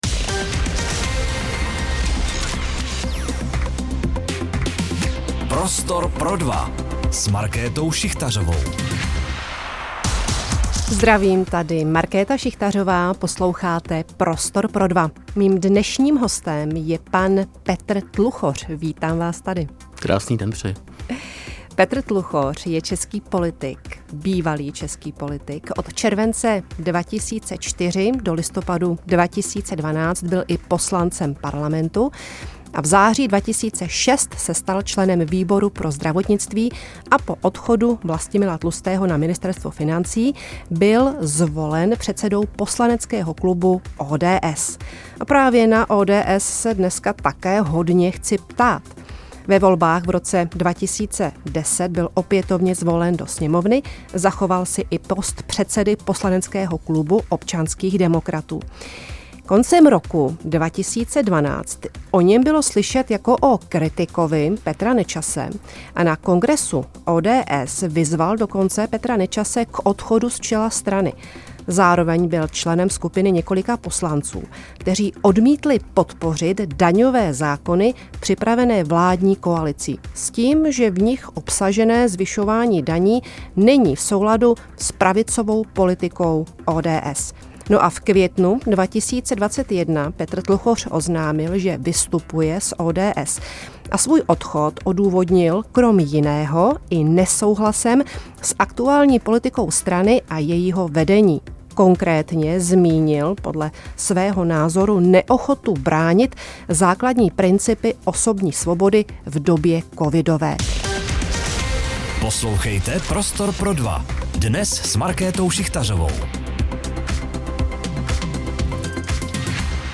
O stavu dnešní politiky, demokracii nebo svobodě slova si Markéta Šichtařová povídala s bývalým poslancem Parlamentu ČR a tehdejším předsedou poslaneckého klubu ODS Petrem Tluchořem.